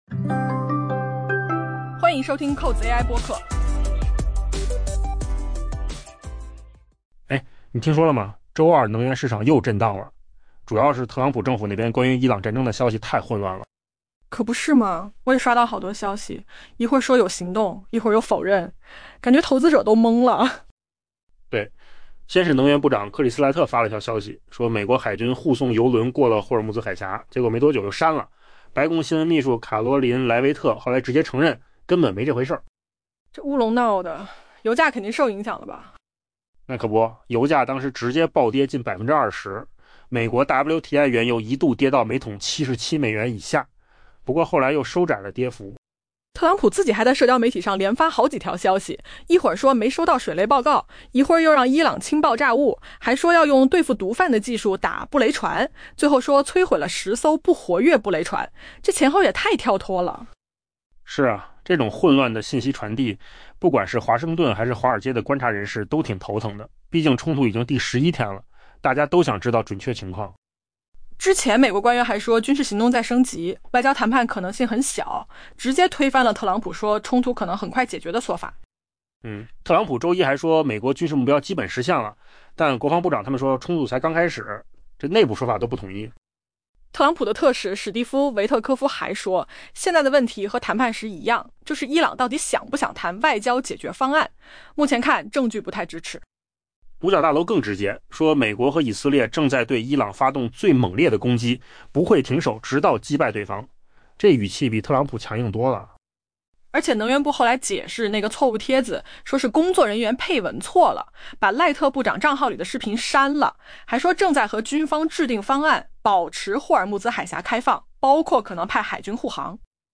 音频由扣子空间生成